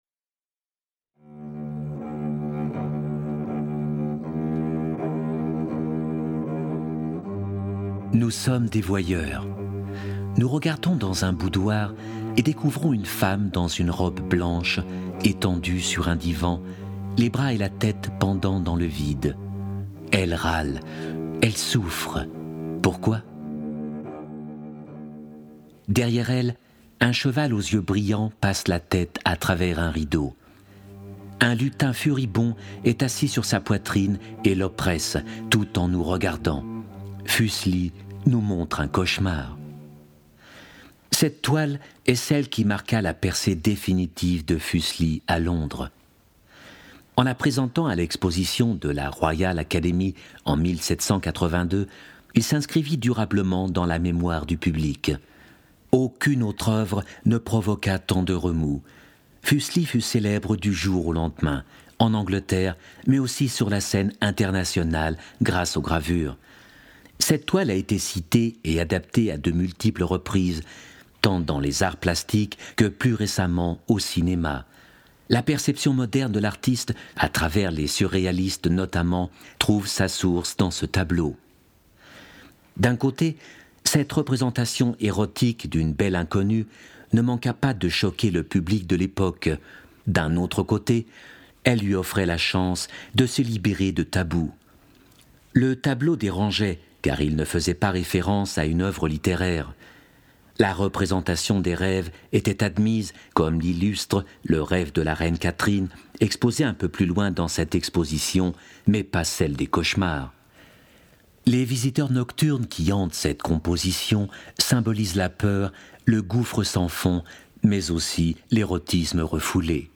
Schauspieler, Sprecher, Regisseur etc .....
Sprechprobe: eLearning (Muttersprache):